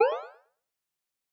051_use_item_01.wav